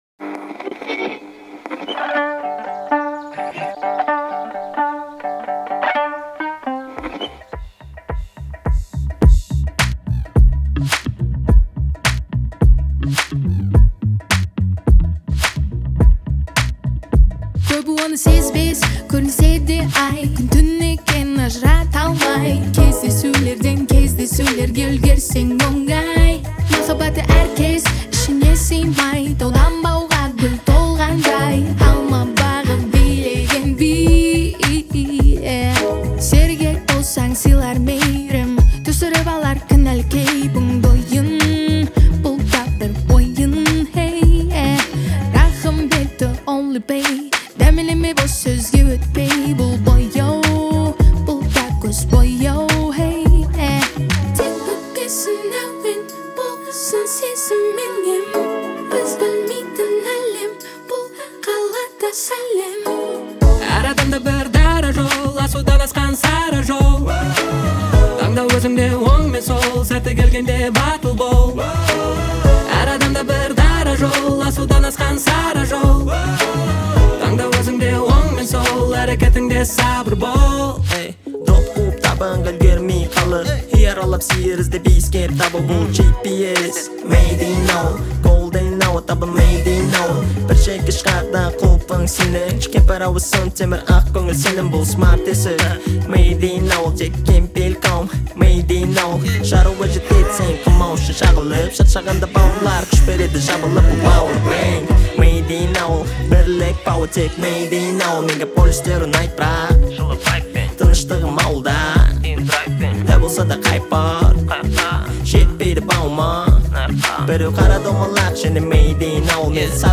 Жанр: Pop | Год: 2021